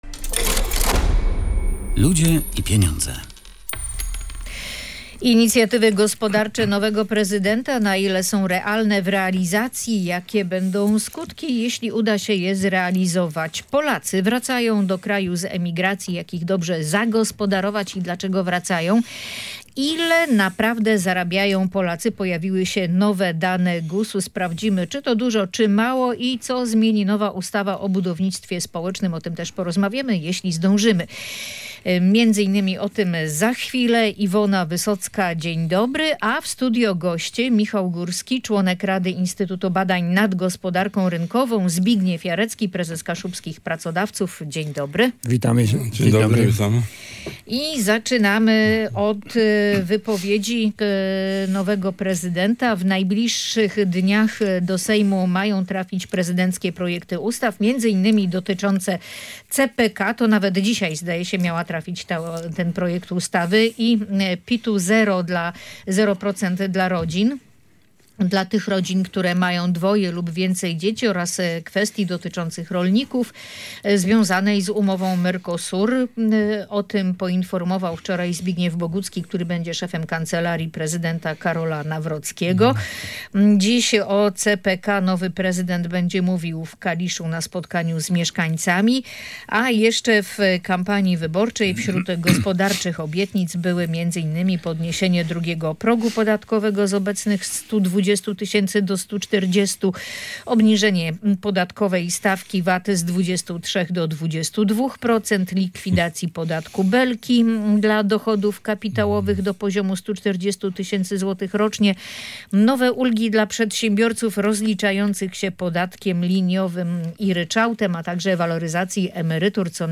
Na ten temat dyskutowali goście audycji „Ludzie i Pieniądze”: